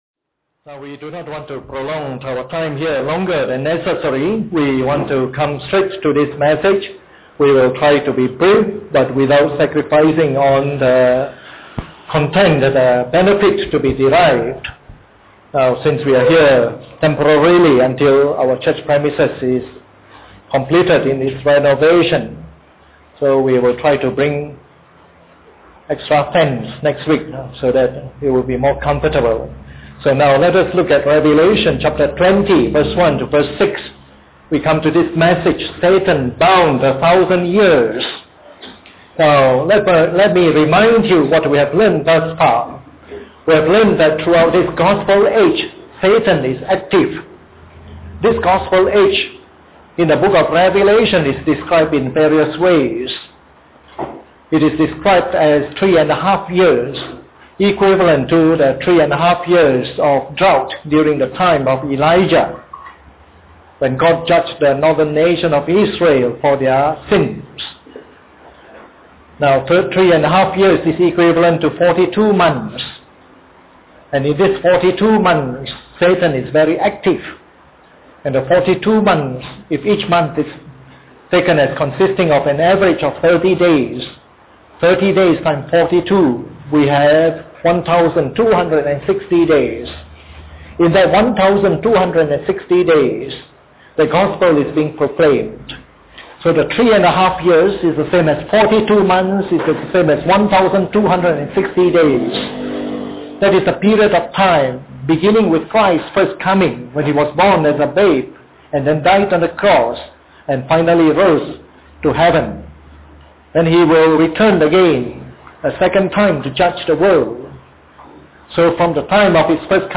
This is part of the morning service series on “Revelation”.